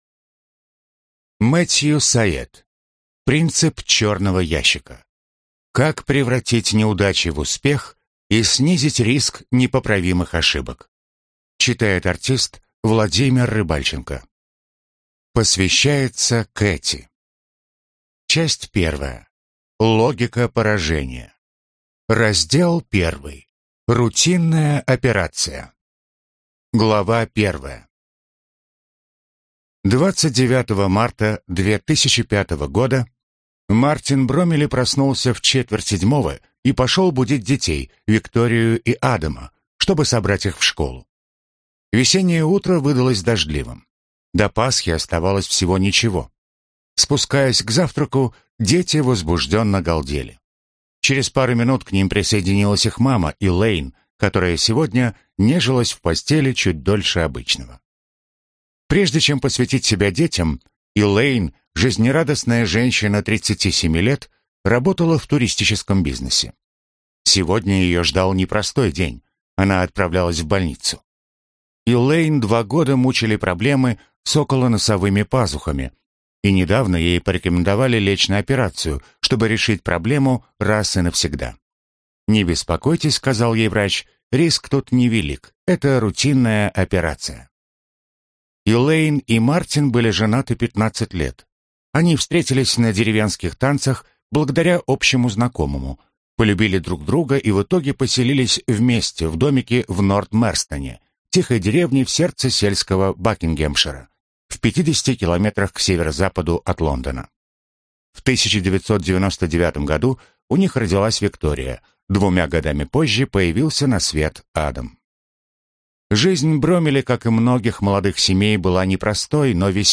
Аудиокнига Принцип «черного ящика». Как превратить неудачи в успех и снизить риск непоправимых ошибок | Библиотека аудиокниг